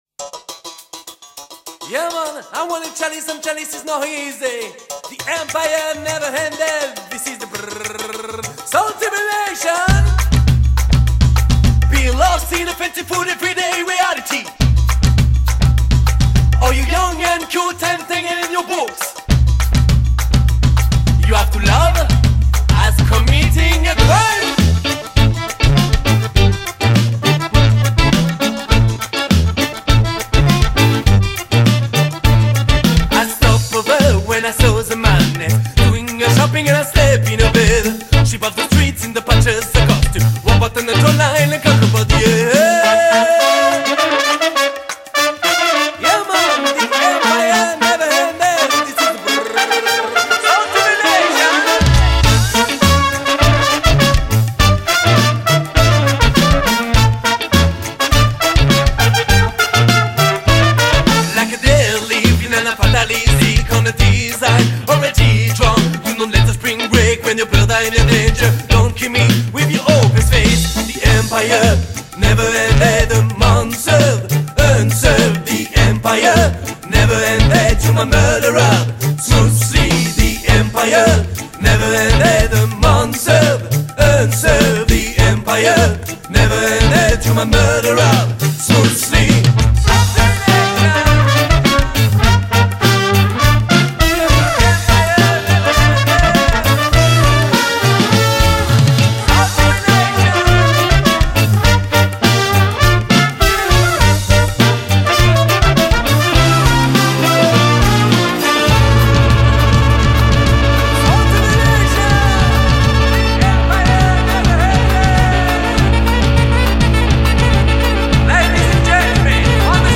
Genre : Alternatif